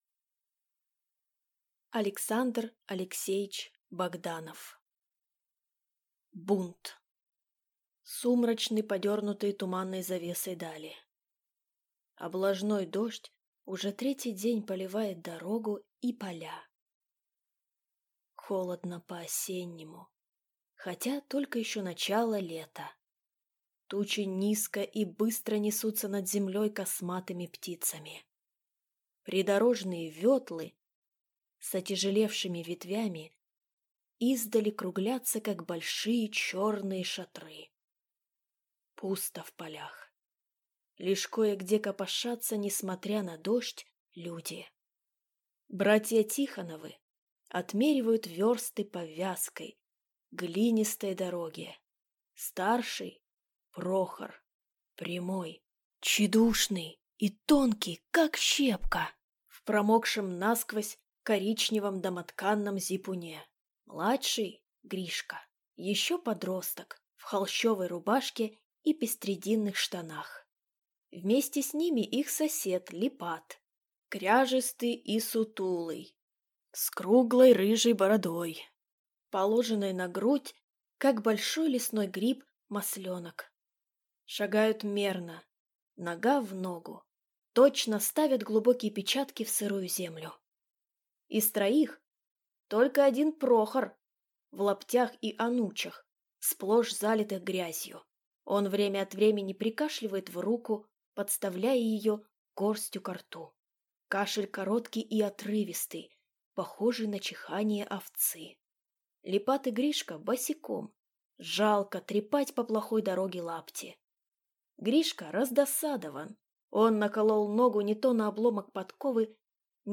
Аудиокнига Бунт | Библиотека аудиокниг